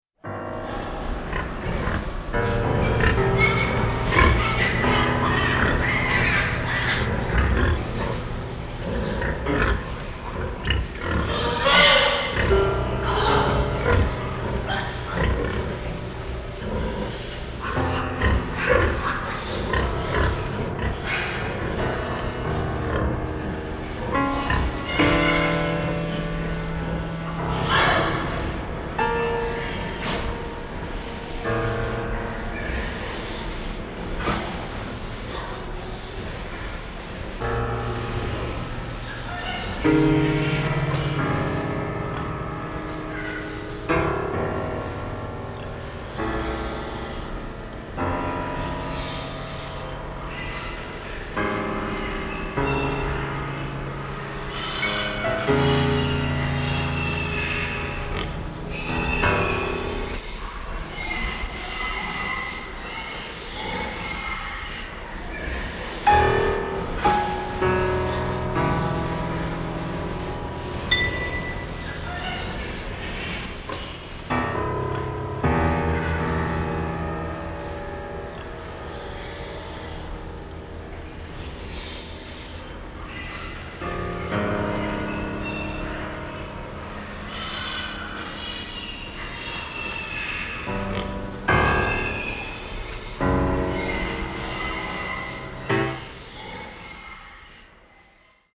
サウンドスケープ　ミニマル　室内楽